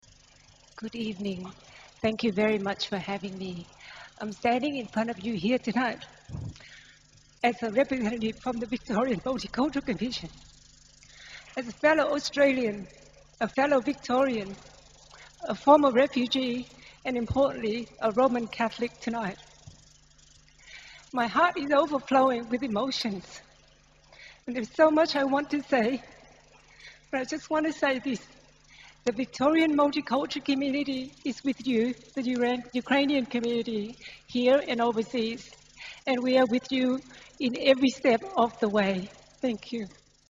Prayer for peace in Ukraine. Ukrainian Catholic Cathedral of the Holy Apostles Peter and Paul.